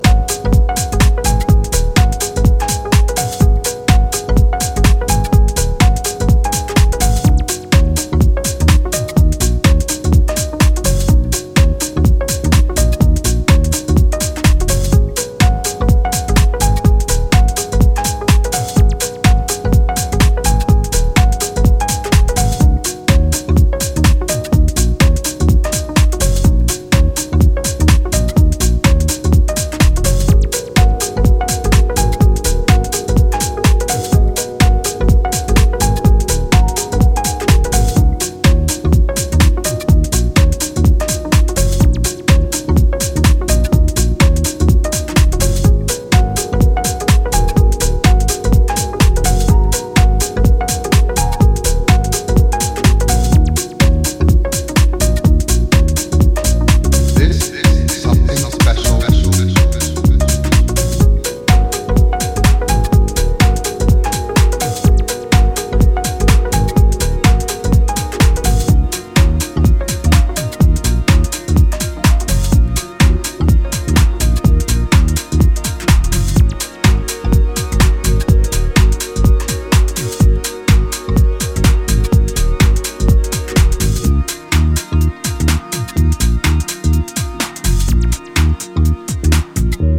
バウンスぎみのベースに気持ち良いメロディアスなシンセサイザーに魅了されるスペシャルなトラック・ワークは流石！
ジャンル(スタイル) DEEP HOUSE